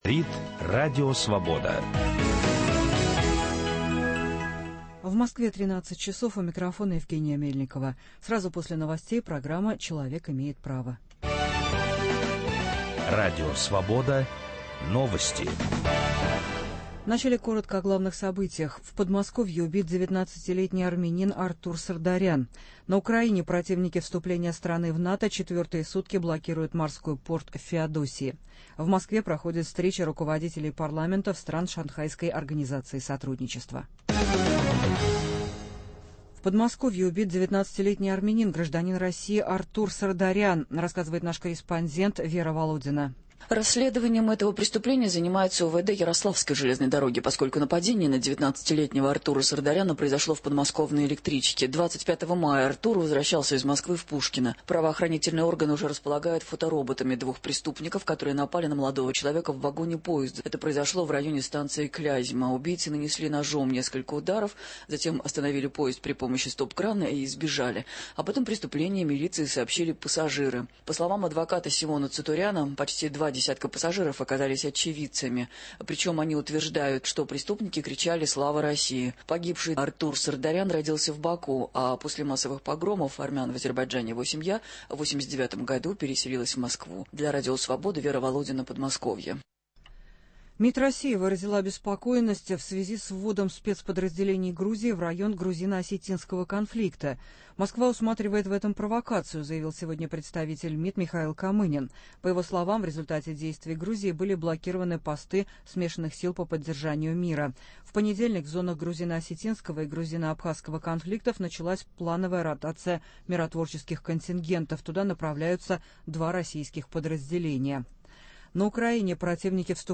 депутат Государственной Думы России Алексей Кондауров